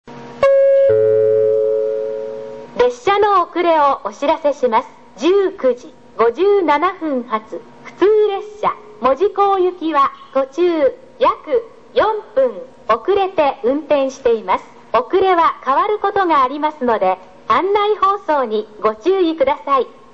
スピーカー：川崎型
遅延放送（普通・門司港） (98KB/20秒)   九州標準C CMT